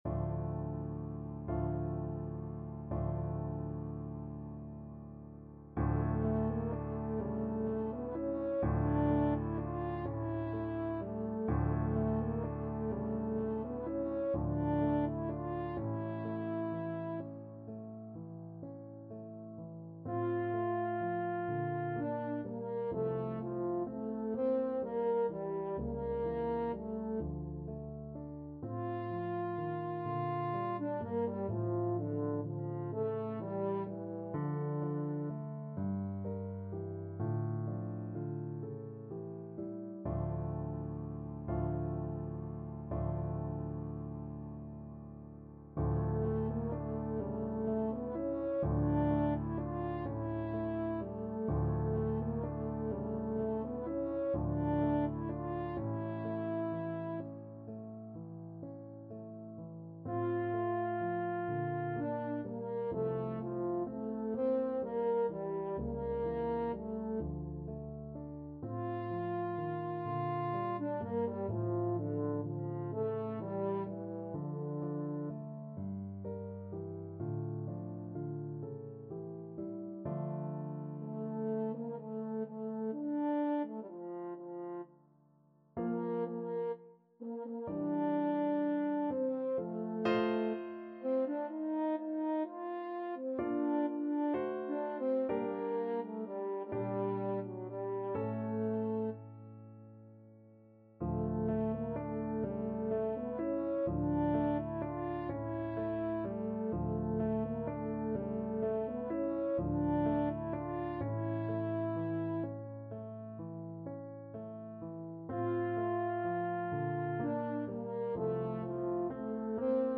French Horn
D minor (Sounding Pitch) A minor (French Horn in F) (View more D minor Music for French Horn )
6/8 (View more 6/8 Music)
= 42 Andante con moto (View more music marked Andante con moto)
Classical (View more Classical French Horn Music)